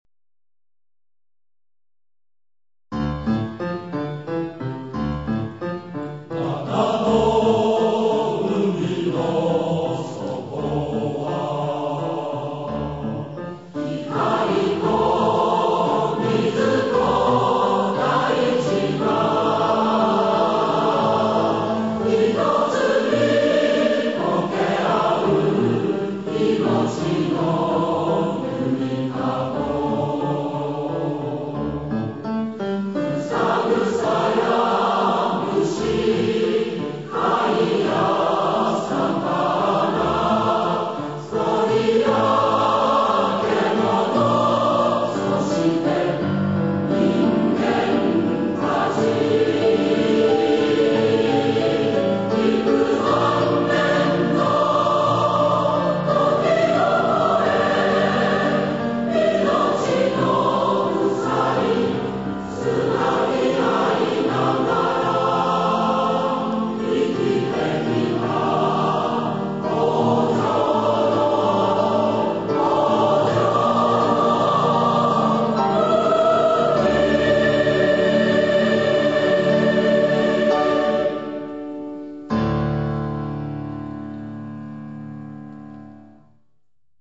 演奏：九州のうたごえ合同合唱団（２００３年九州のうたごえ大分祭典での録音）